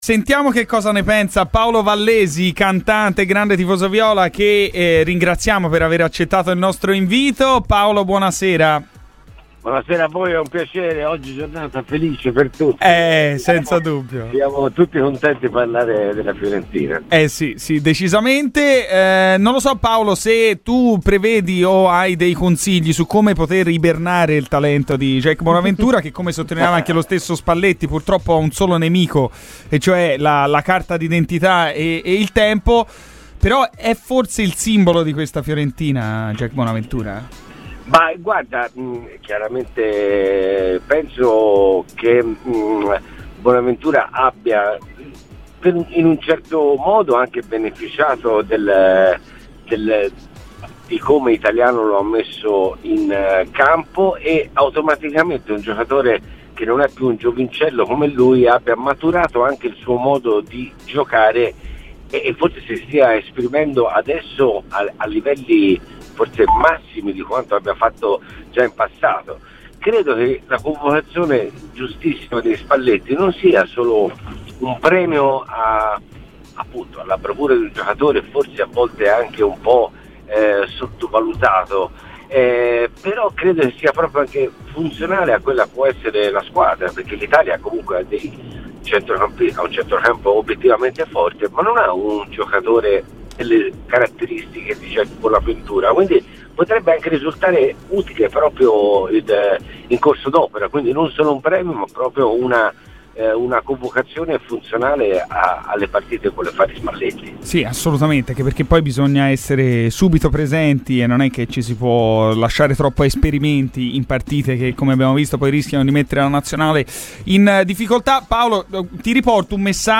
Il noto cantante di fede viola Paolo Vallesi è intervenuto ai microfoni di RadioFirenzeViola.